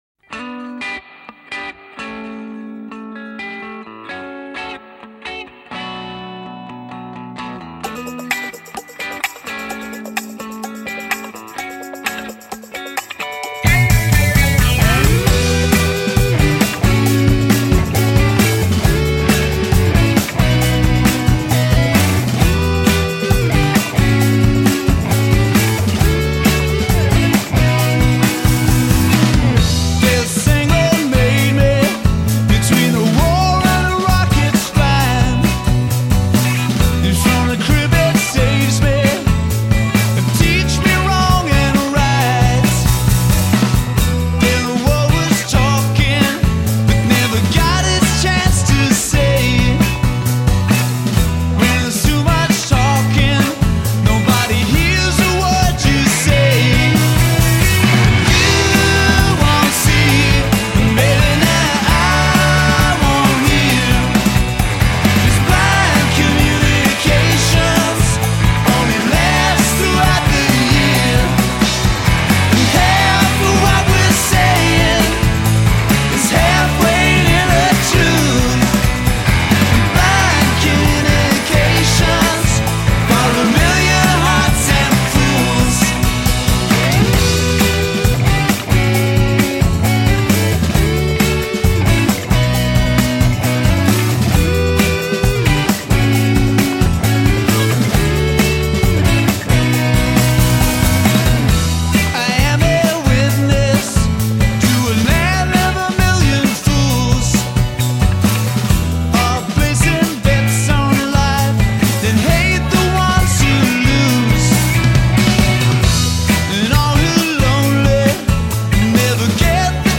classic rock
which is certain to evoke the feel of music of decades ago